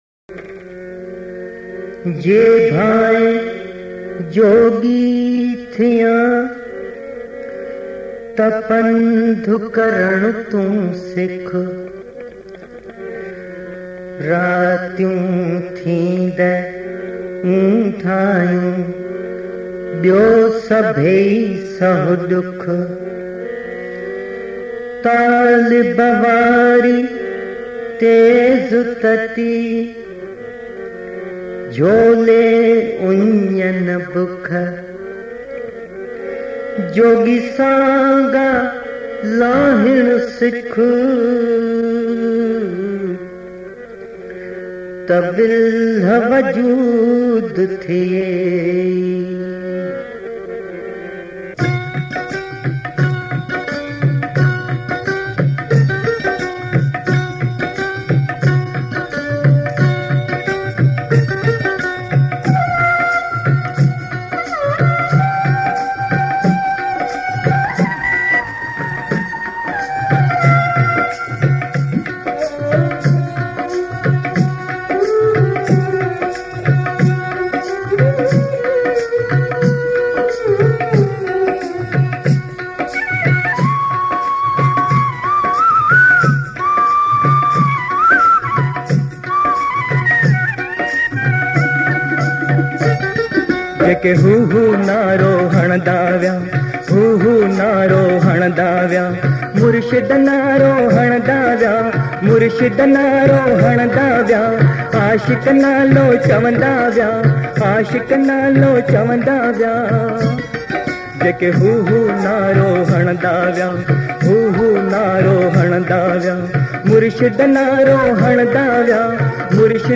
Sindhi Kalam, Geet, Qawali, Duet